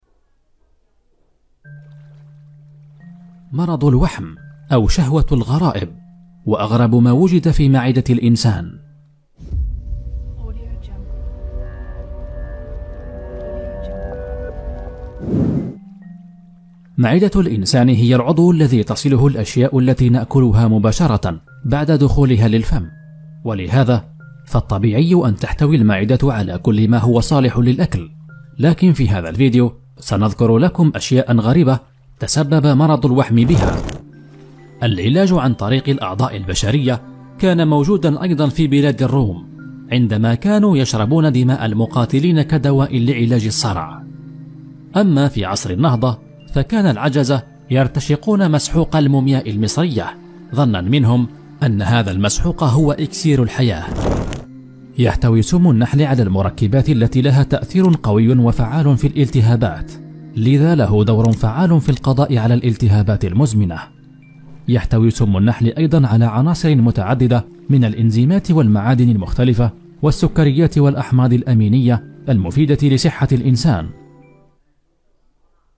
男阿13 阿拉伯语男声 纪录片 低沉|激情激昂|大气浑厚磁性|沉稳|娓娓道来|科技感|积极向上|神秘性感|调性走心|素人